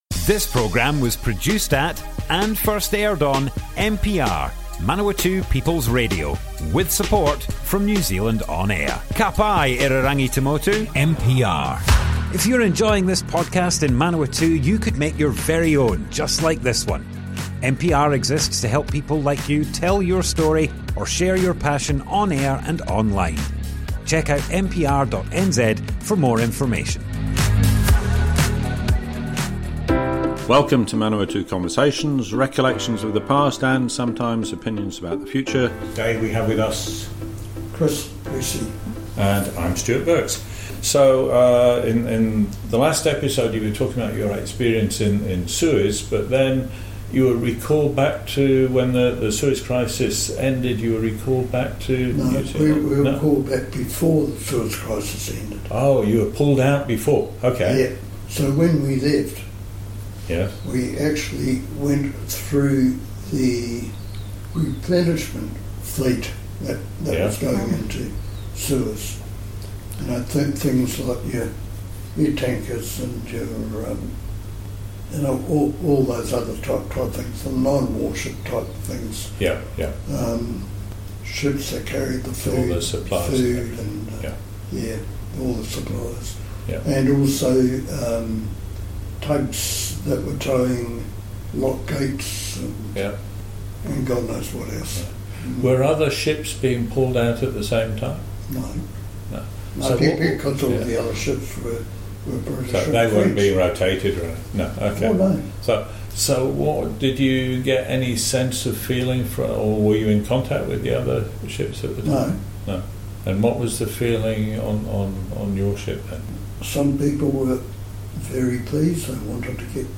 Manawatu Conversations More Info → Description Broadcast on Manawatu People's Radio, 10th June 2025.
oral history